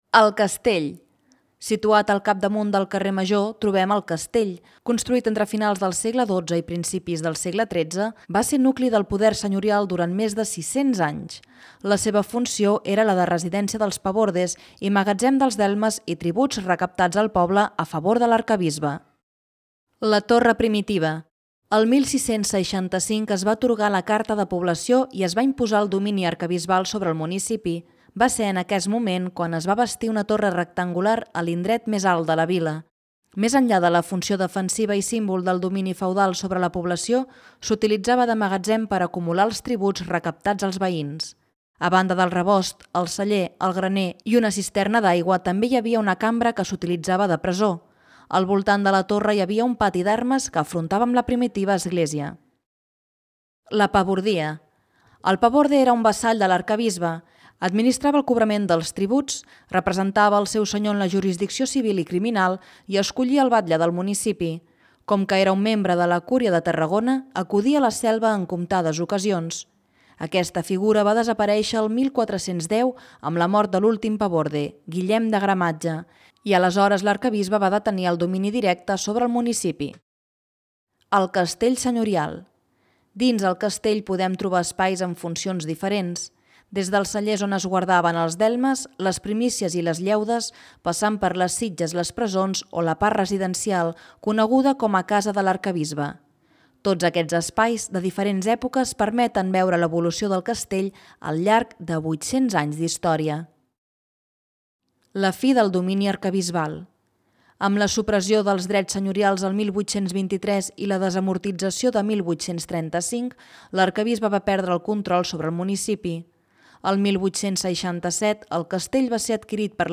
Audio guia